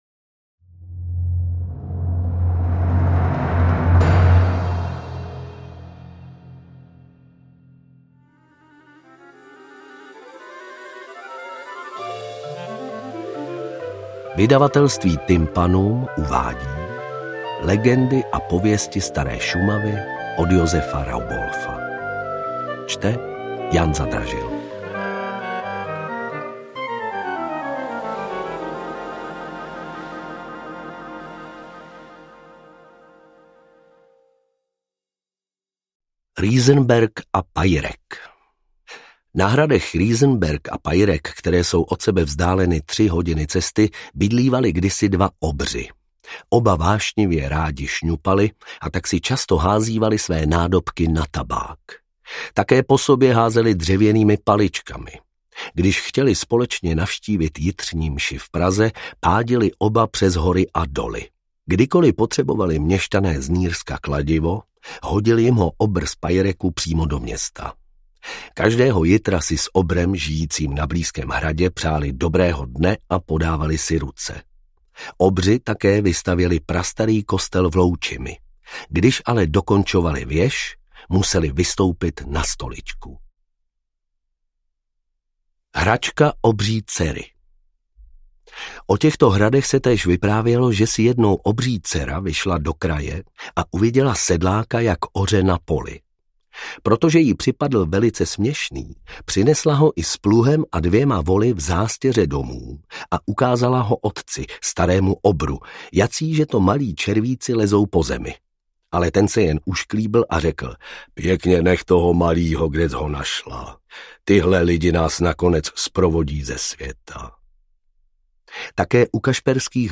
AudioKniha ke stažení, 13 x mp3, délka 4 hod. 41 min., velikost 258,5 MB, česky